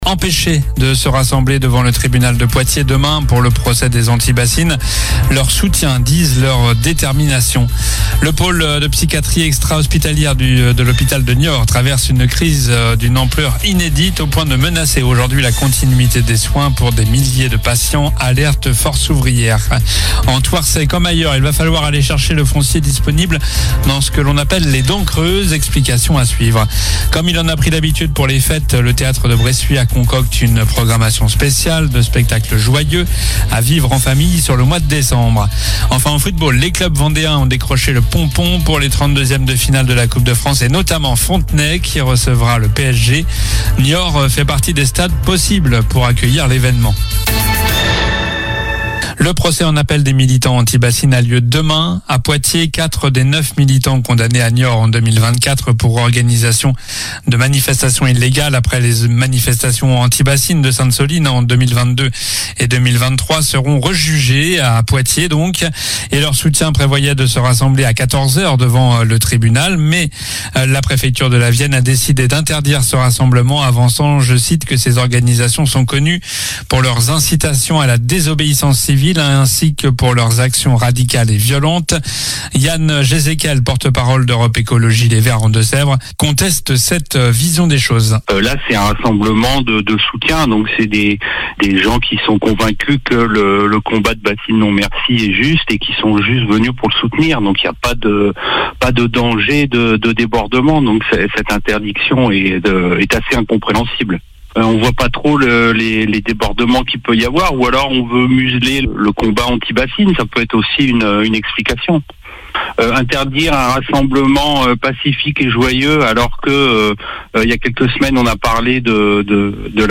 Journal du mardi 2 décembre (soir)
infos locales